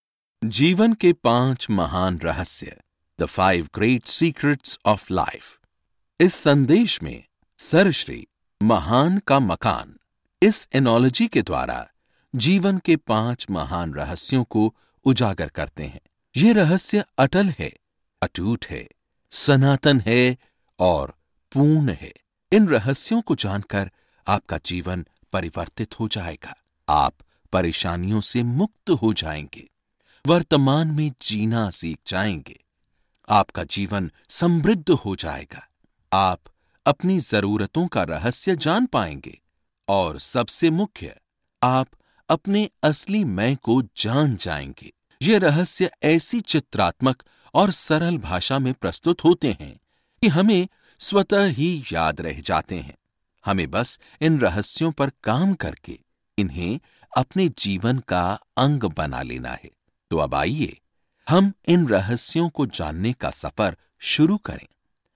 Sprechprobe: eLearning (Muttersprache):